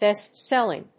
音節bést‐séll・ing 発音記号・読み方
/ˈbɛˈstsɛlɪŋ(米国英語), ˈbeˈstselɪŋ(英国英語)/